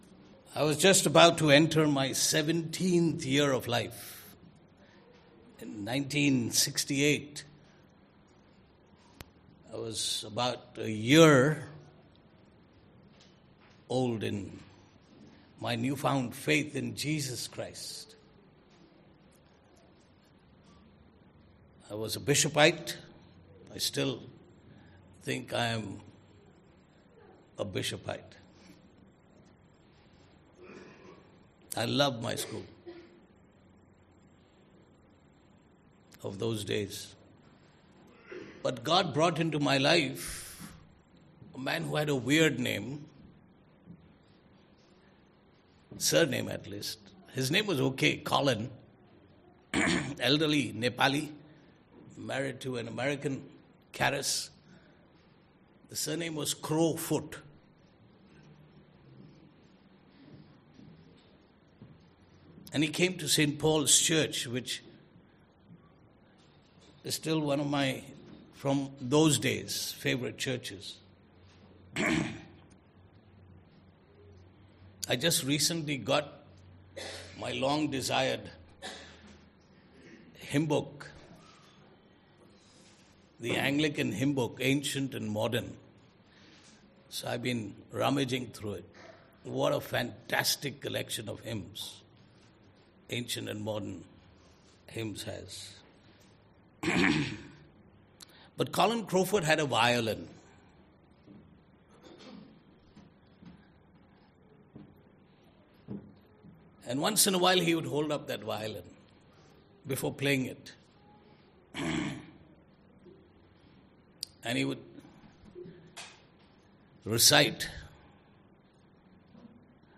Service Type: Sunday Morning
Region-1_18-Jan-Sermon.mp3